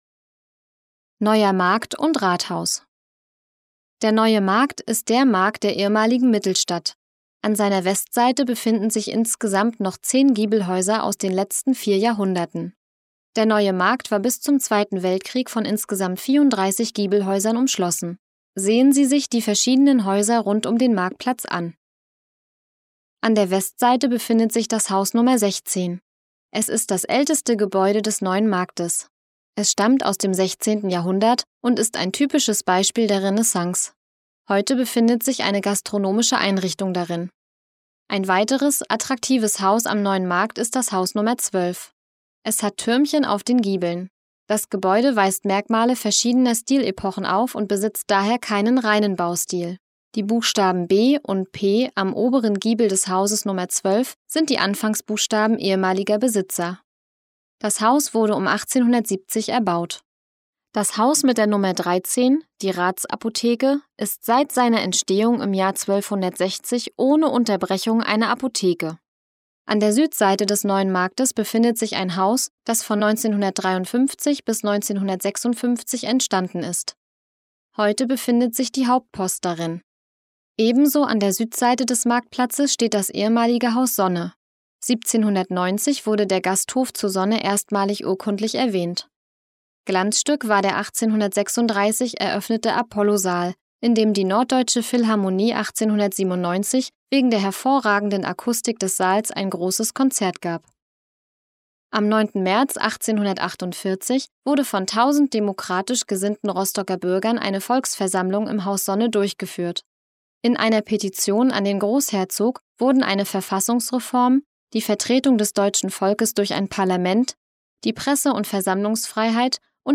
Audioguide Rostock - Station 10: Neuer Markt und Rathaus